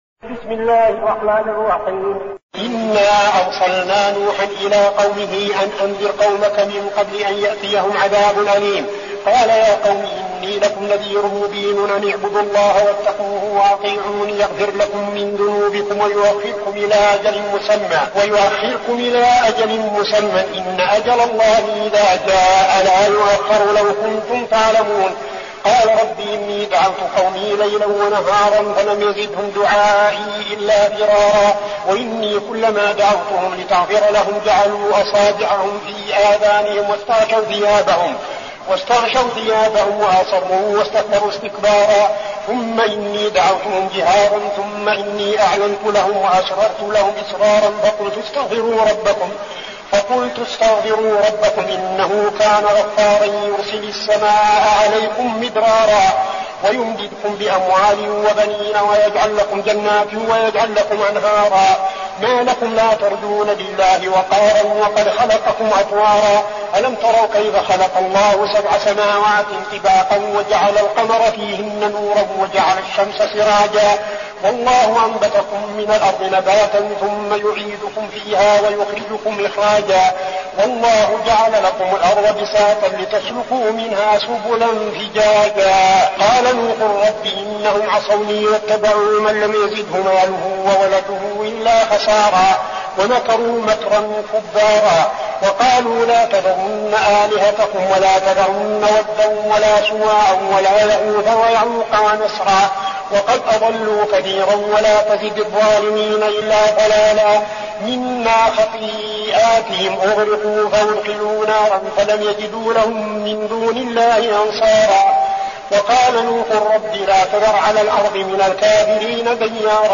المكان: المسجد النبوي الشيخ: فضيلة الشيخ عبدالعزيز بن صالح فضيلة الشيخ عبدالعزيز بن صالح نوح